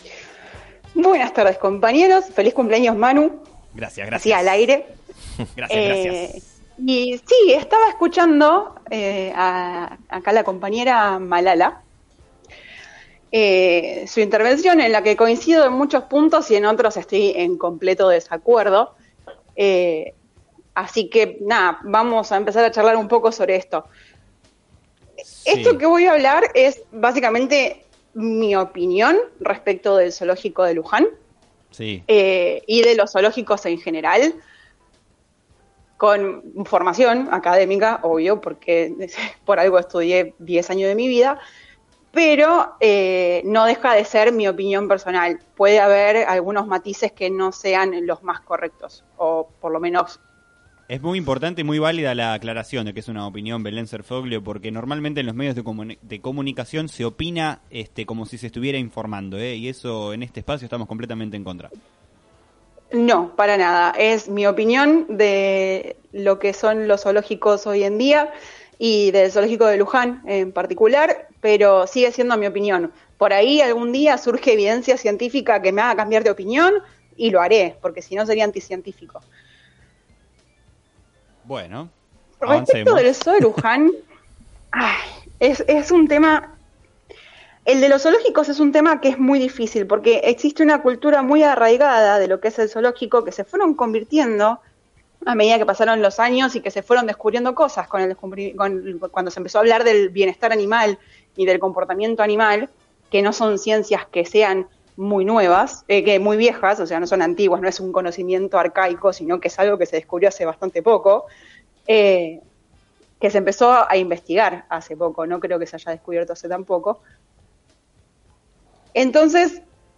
En su columna sobre cuidado animal en el programa “Sobre Las Cartas La Mesa”